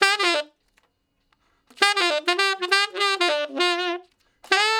066 Ten Sax Straight (D) 02.wav